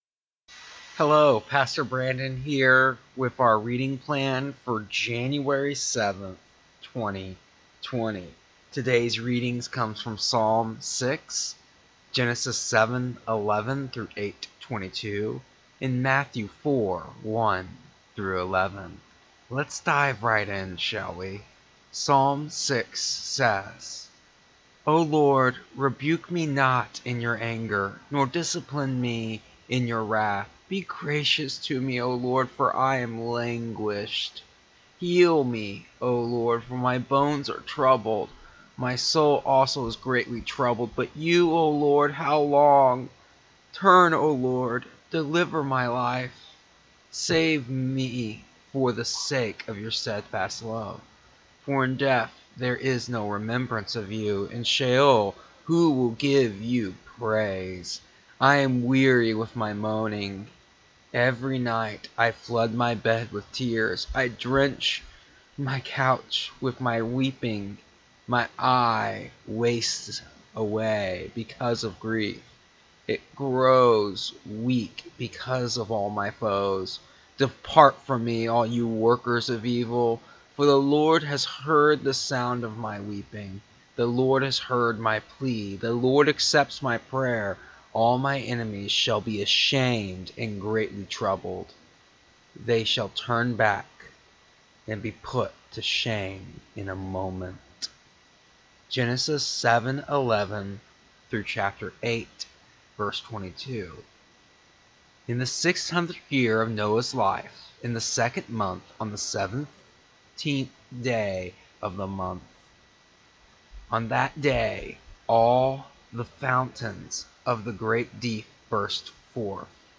Here is the audio version and daily devotional for, January 7th, 2020, of our daily reading plan.